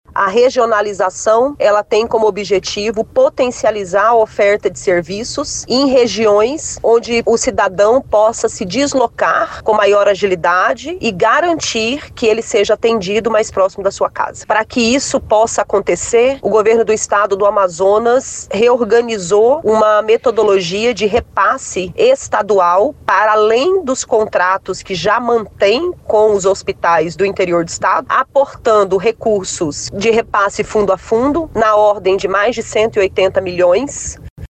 Sonora-1-Nayara.mp3